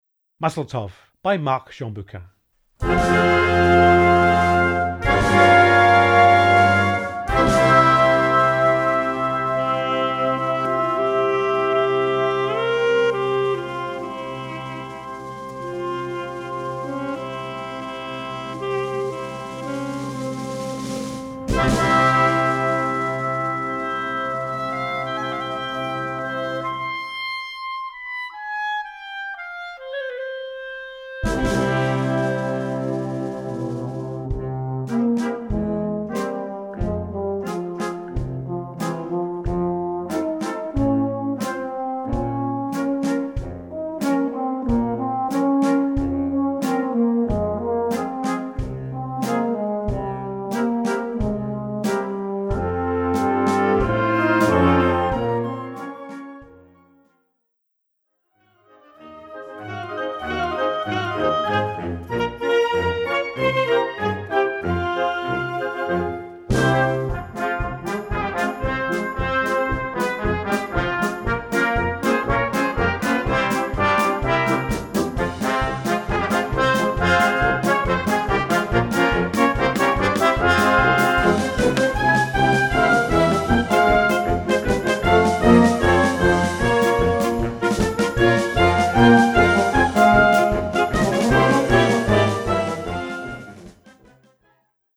Gattung: Konzertwerk
Besetzung: Blasorchester
Ein mitreißendes Stück zum Feiern!